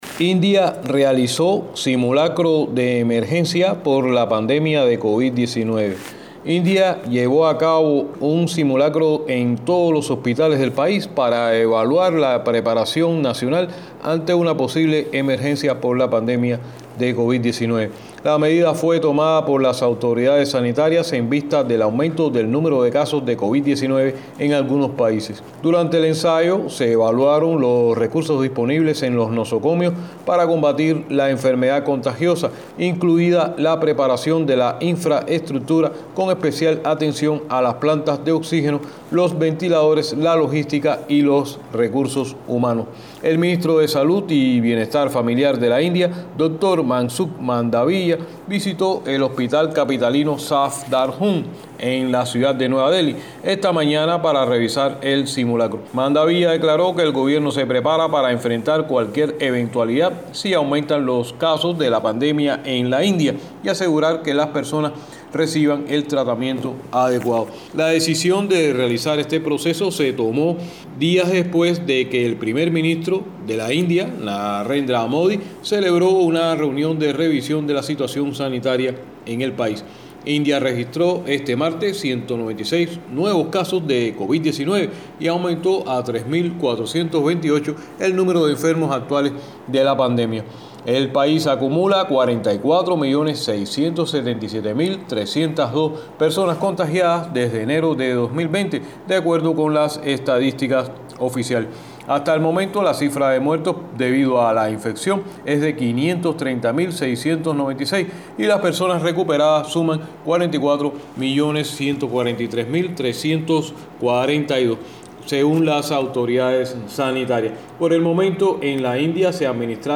desde Nueva Delhi